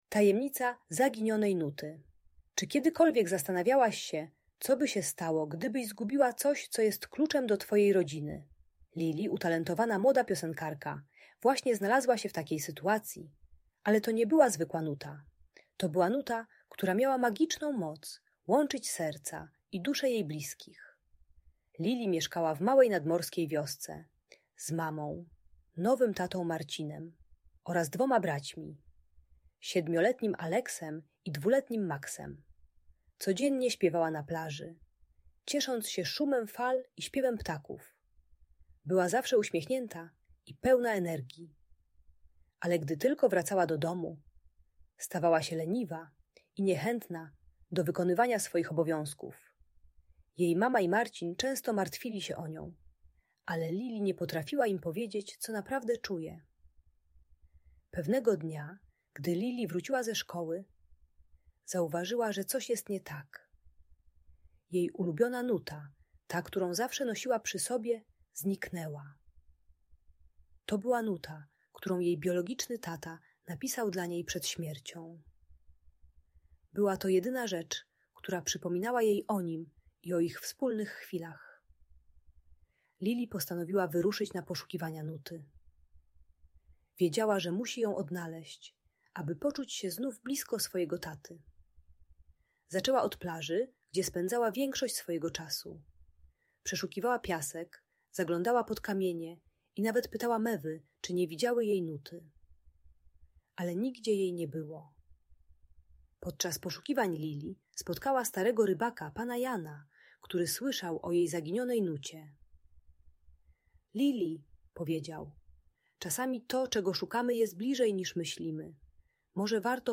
Tajemnica Zaginionej Nuty - Emocje rodzica | Audiobajka